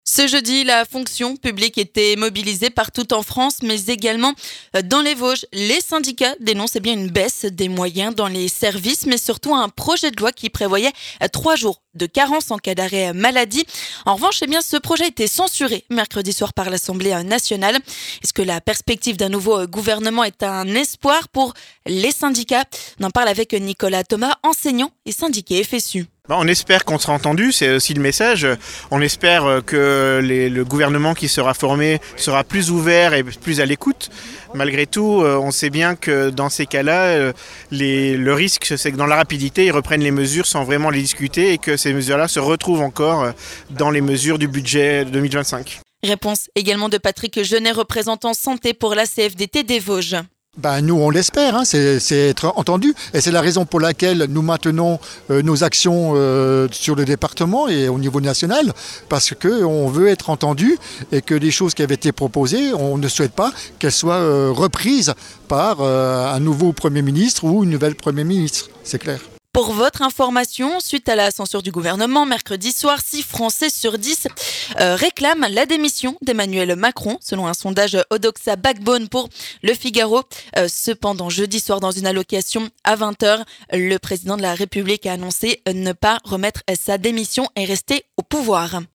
Nous sommes allés à la rencontre de deux syndiqués pour leur demander si la perspective d'un nouveau Premier ministre pourrait être un espoir pour la fonction publique.